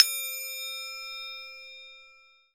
TMPL BELL  L.WAV